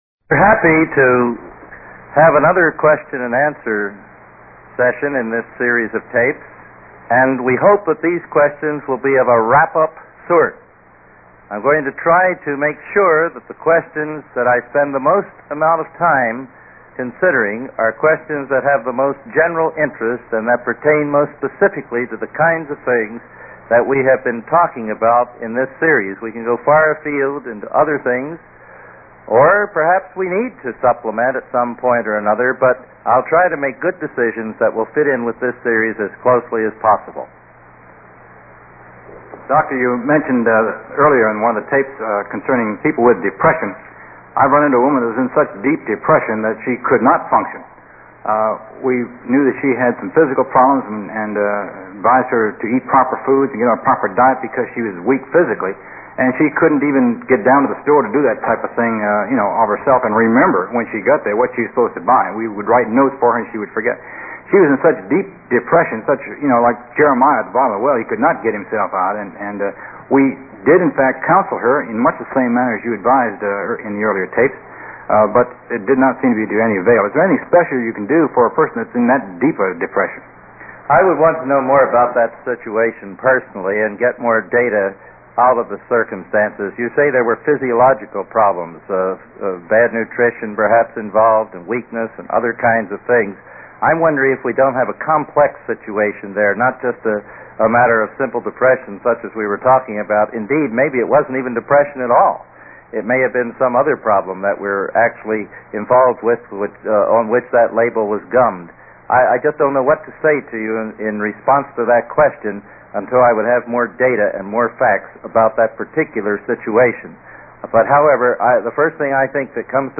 Biblical Counseling Q&A